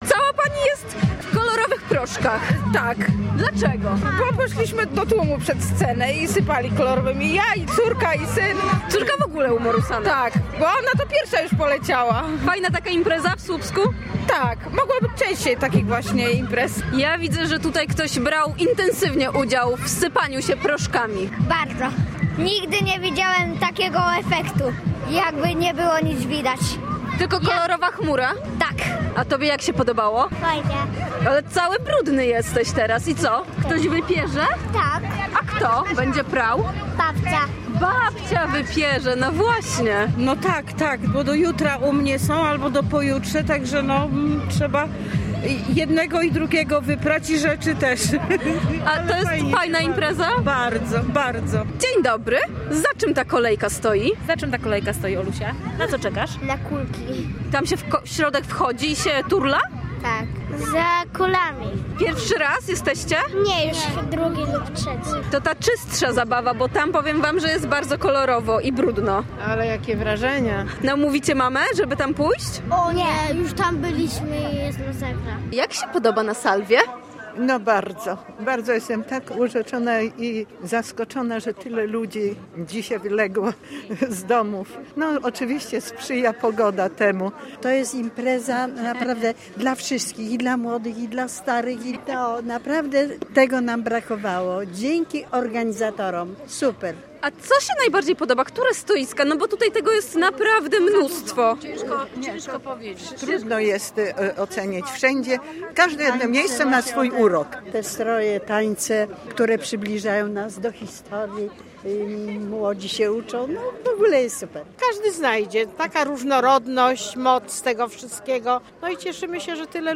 Imprezę na słupskich bulwarach odwiedziło kilkadziesiąt tysięcy ludzi. Były spektakle, koncerty, występy dzieci, nauka tanga, stoiska z rękodziełem.